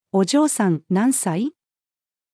お嬢さん、何歳？-female.mp3